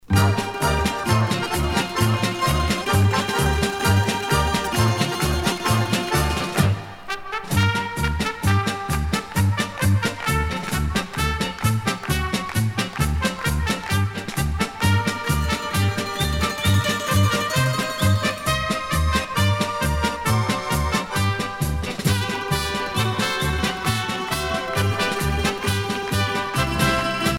danse : kazatchok
Pièce musicale éditée